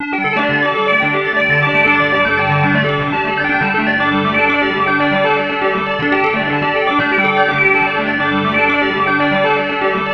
Index of /90_sSampleCDs/USB Soundscan vol.13 - Ethereal Atmosphere [AKAI] 1CD/Partition B/01-SEQ PAD B
SEQ PAD06.-L.wav